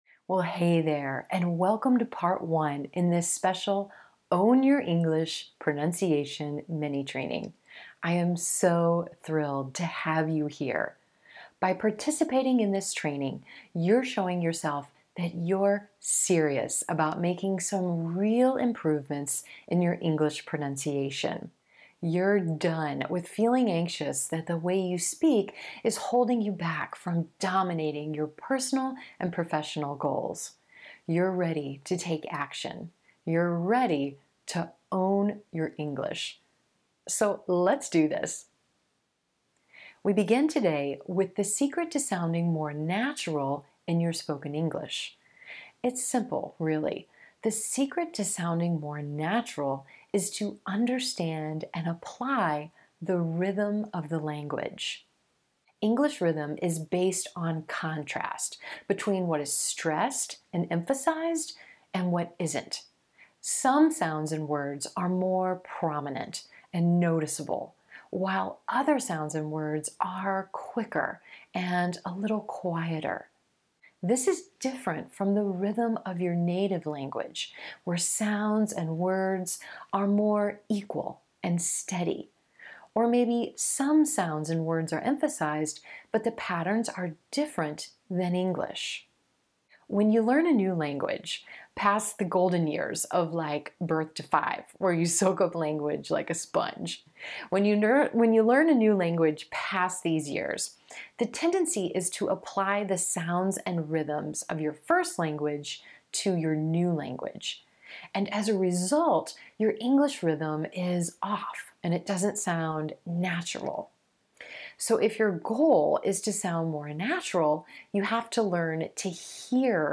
On the go and need the AUDIO of this lesson?